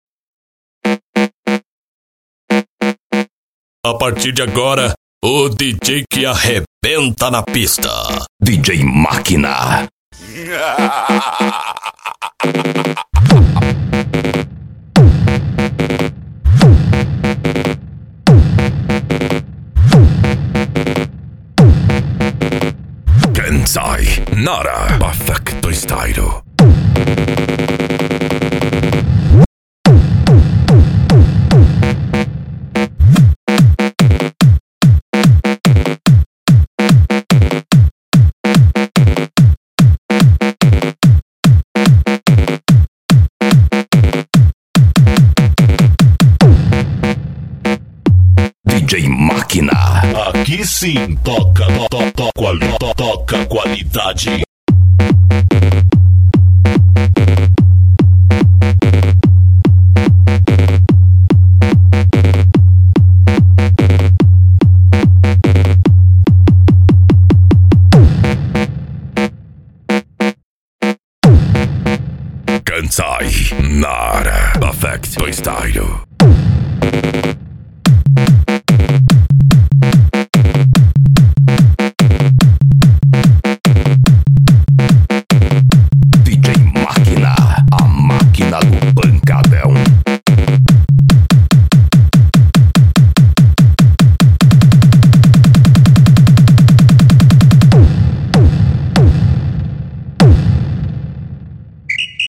Deep House
Eletronica
Hard Style
PANCADÃO